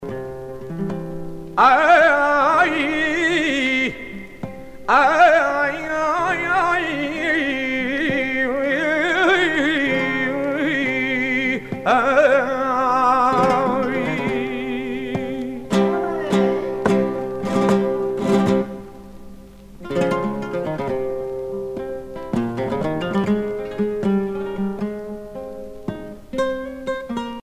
danse : séguédille
Pièce musicale éditée